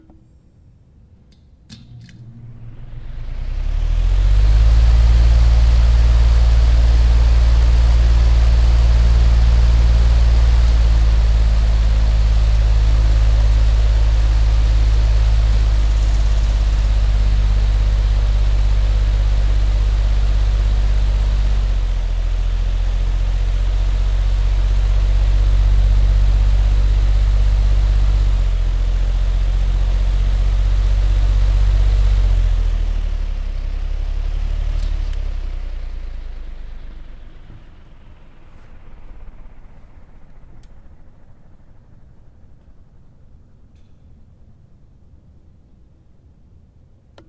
扇子
描述：工业风扇以几种不同的速度吹制。
Tag: 空气 背景 风扇 工业